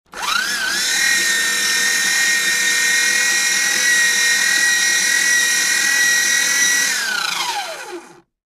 Paper Shredder
Paper shredder destroys documents.